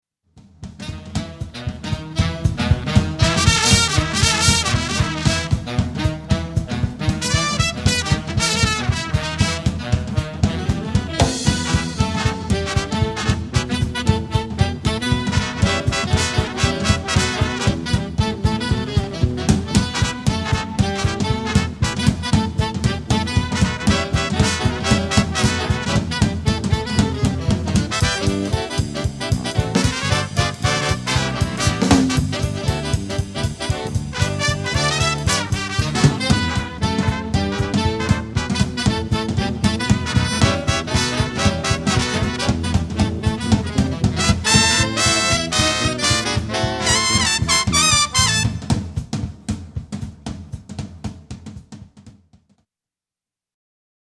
Classic Big Band